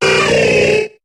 Cri de Tygnon dans Pokémon HOME.